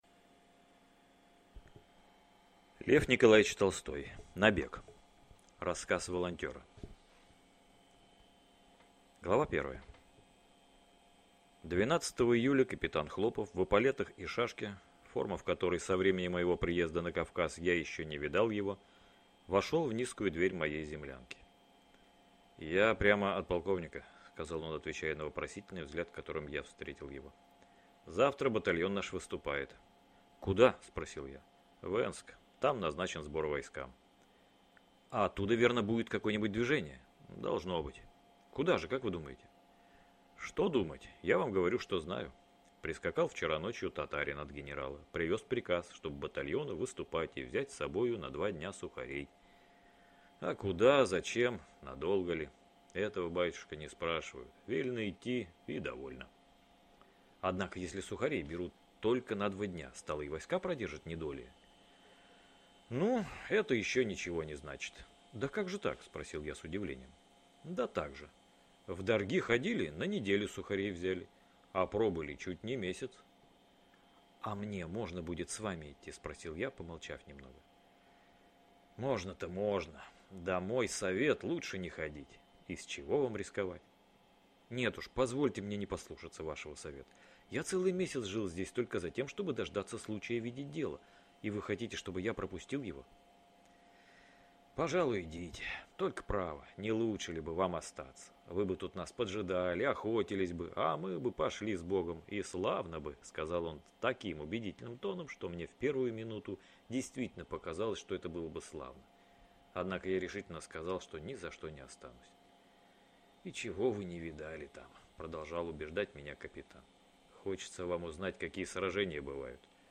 Аудиокнига Набег | Библиотека аудиокниг